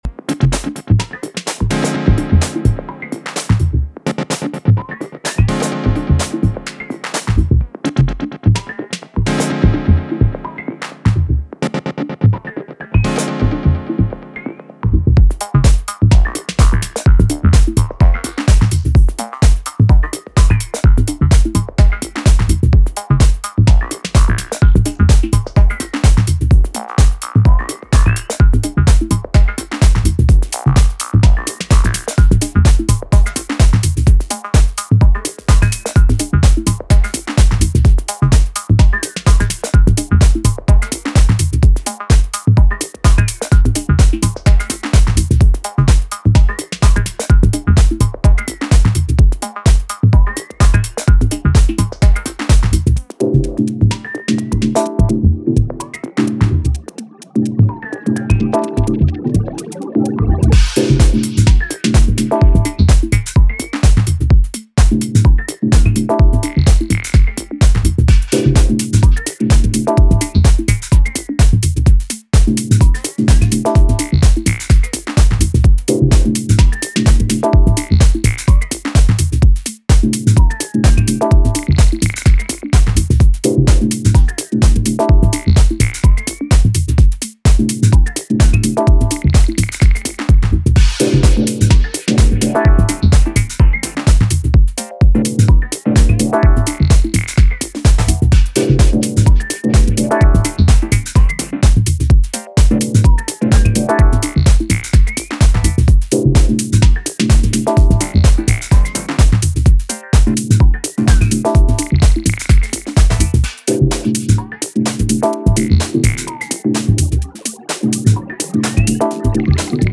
House Minimal